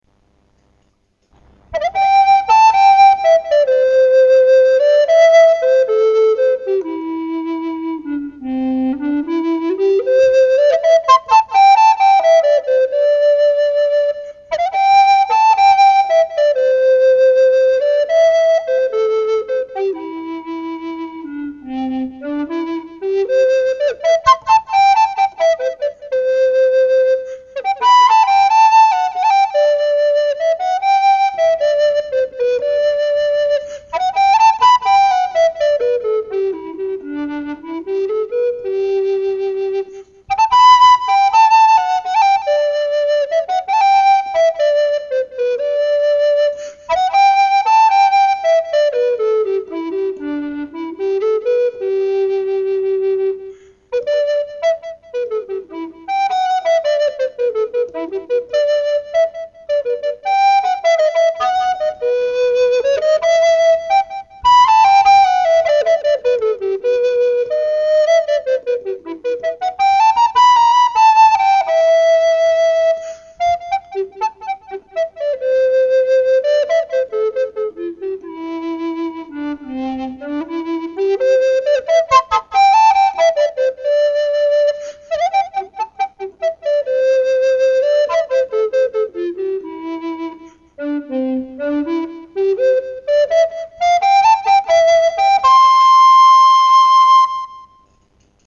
Essence of American Patriots (A. S. Bowman), tenor recorder